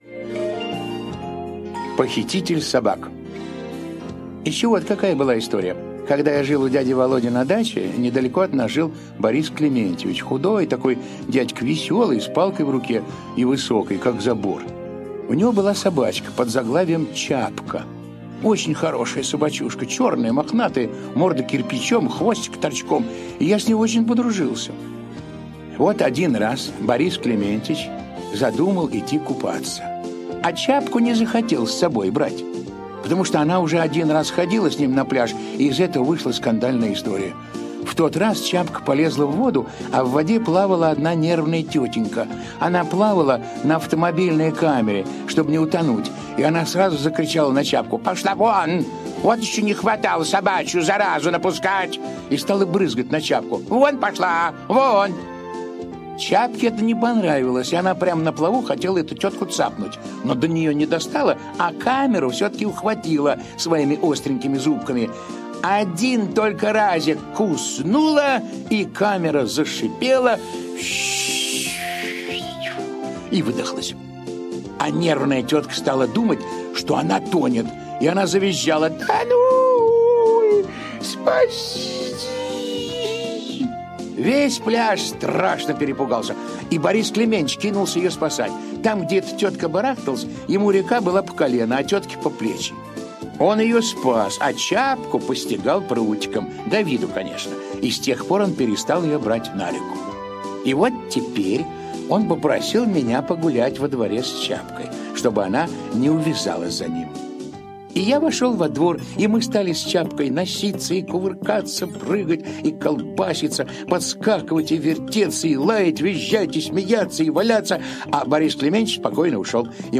Похититель собак - аудио рассказ Драгунского. Рассказ о том, как Дениска случайно стал похитителем собак.